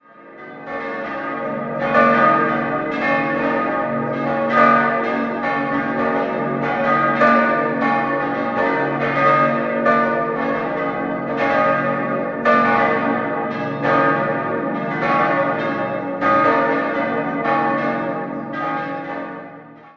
Jahrhundert. 5-stimmiges Geläut: b°-des'-es'-ges'-as' Die Glocken wurden 1949 von Karl Hamm in Regensburg gegossen.